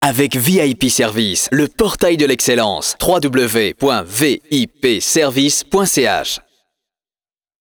écouter en live les annonces radio ci-jointes, elle démarre après l'introduction suivante ''l'horoscope vous est offert par...''